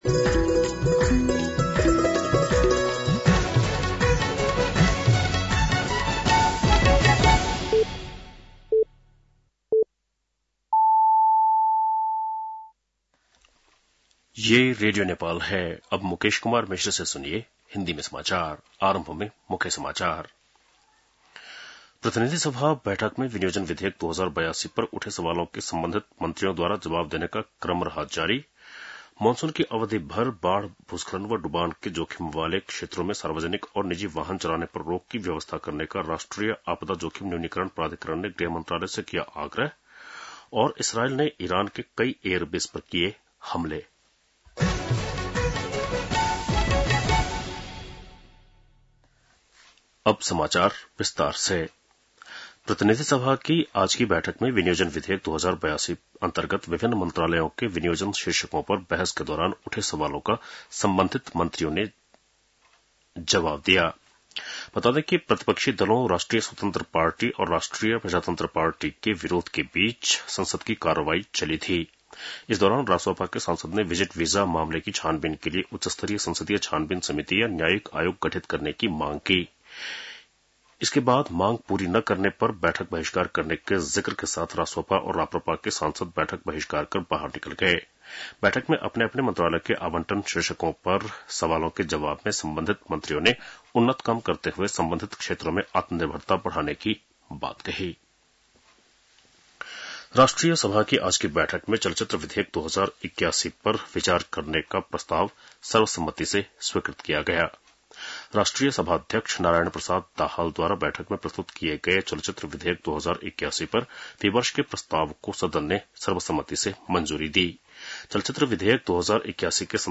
बेलुकी १० बजेको हिन्दी समाचार : ९ असार , २०८२
10-PM-Hindi-NEWS-3-09.mp3